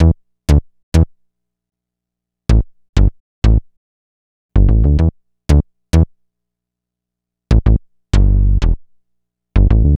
Bass 38.wav